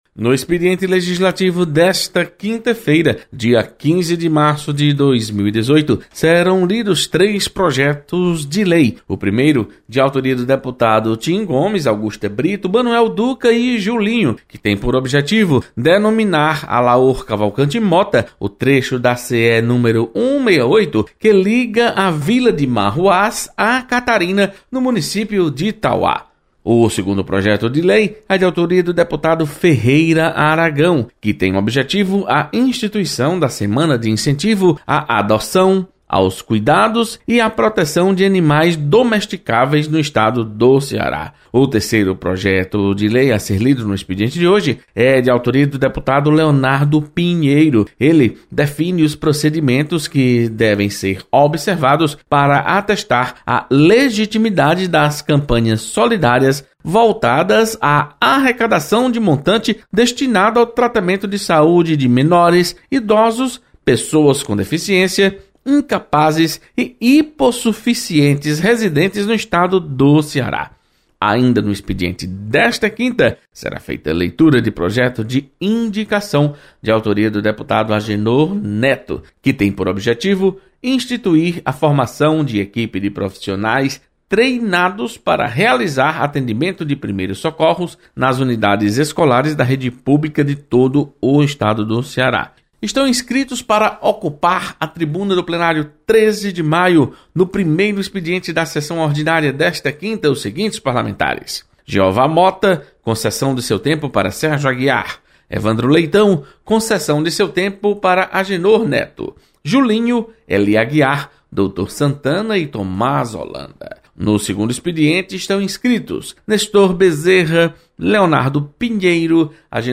Acompanhe as informações do expediente legislativo desta quinta-feira. Repórter